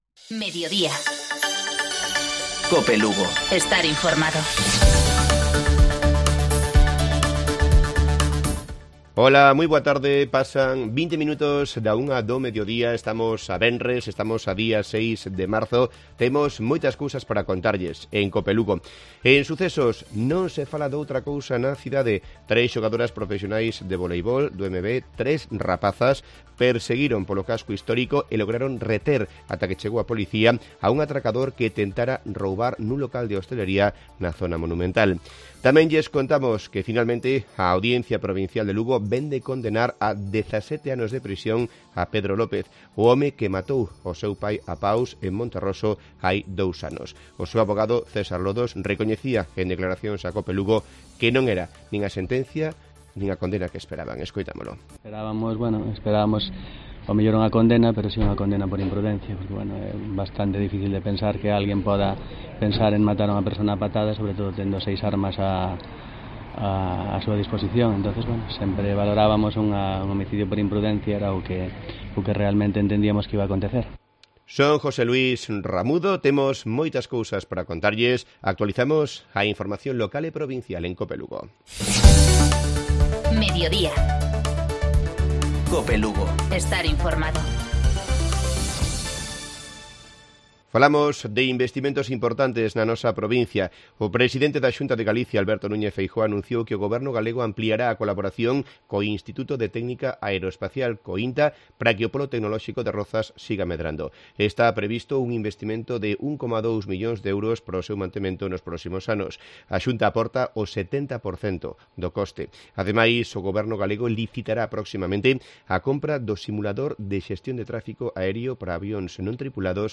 Informativo Mediodía Cope Lugo.